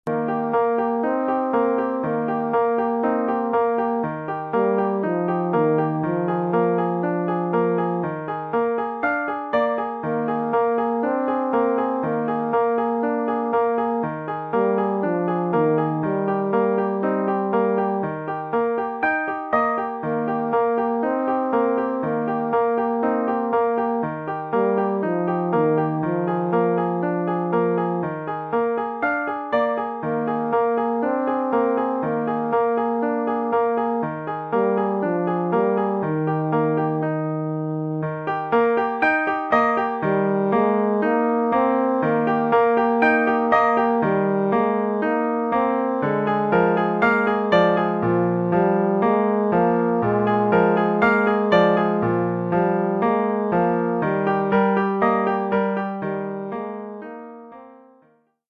euphonium / tuba et piano.